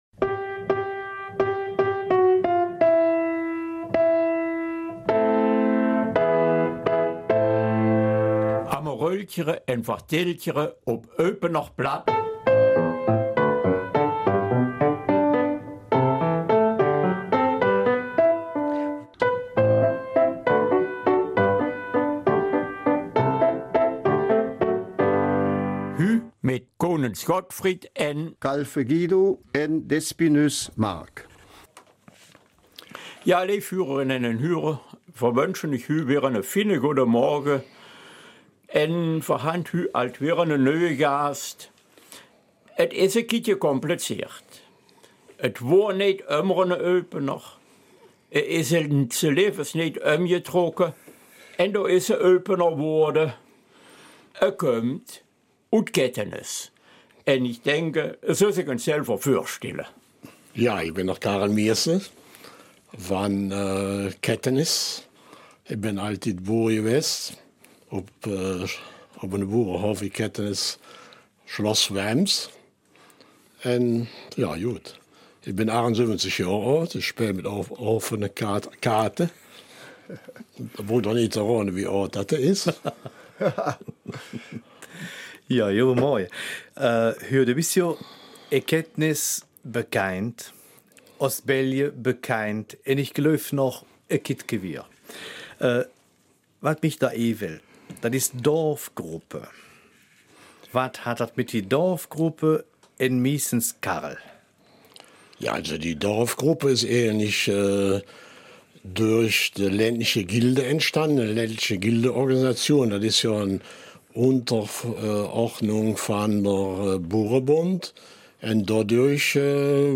Eupener Mundart: Gelebtes Kettenis